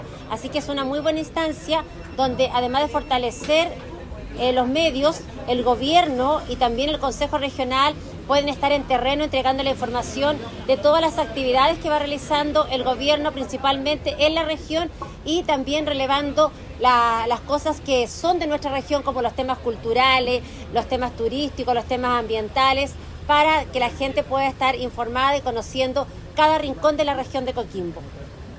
Por su parte, la consejera regional Paola Cortés, valoró el aporte a la descentralización y al derecho a la comunicación que se da a través de esta iniciativa impulsada por el gobierno.
PAOLA-CORTES-CONSEJERA-REGIONAL.mp3